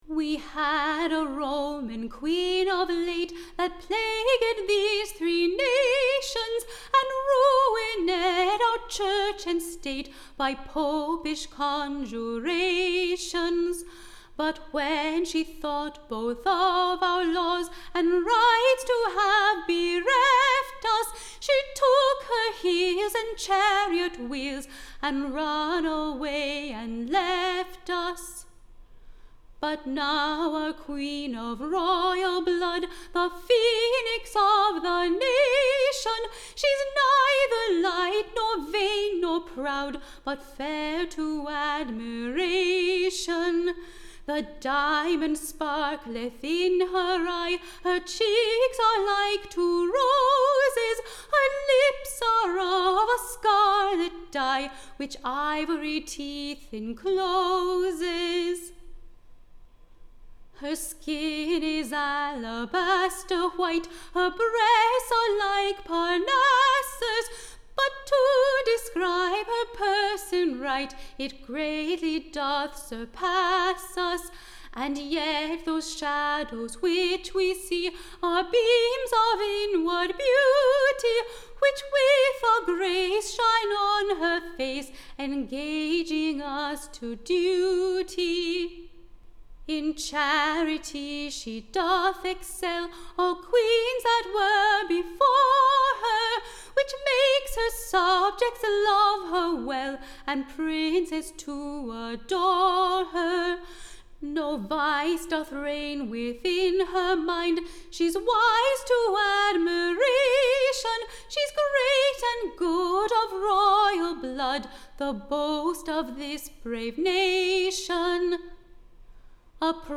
Tune Imprint To the Tune of Good Health to Betty: Or, Queen Marys Lamentation.